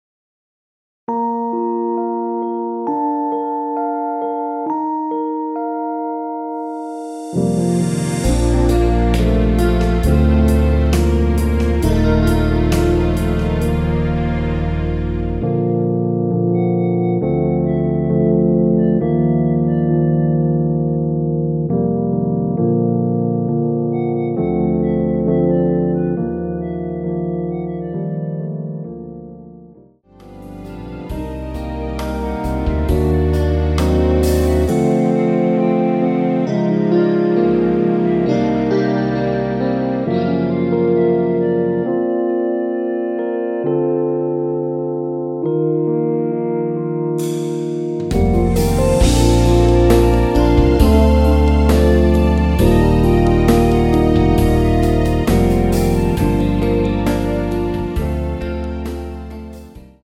Bb
멜로디 MR이라고 합니다.
앞부분30초, 뒷부분30초씩 편집해서 올려 드리고 있습니다.
중간에 음이 끈어지고 다시 나오는 이유는